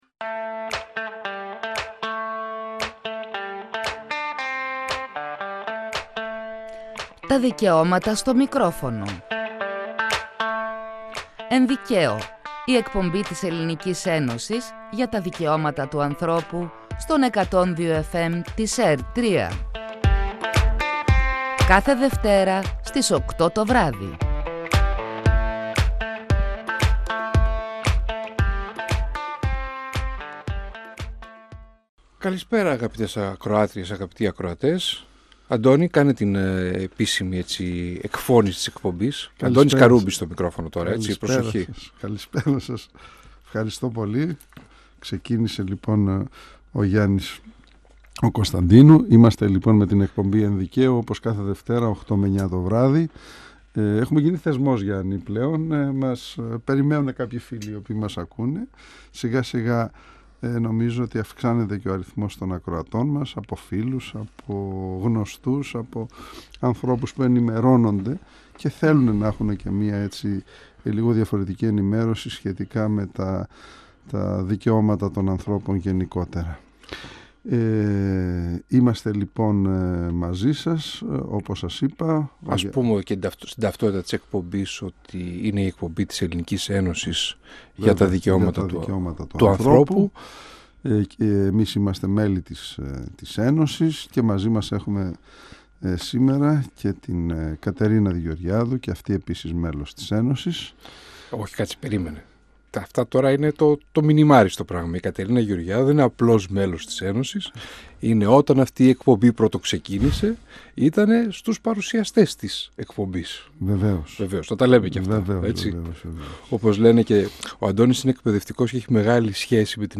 Η έμφυλη βία κι η εκδικητική πορνογραφία ήταν το βασικό θέμα συζήτησης στην εκπομπή «Εν Δικαίω» του 102fm της ΕΡΤ3.